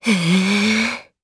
Erze-Vox_Casting2_jp.wav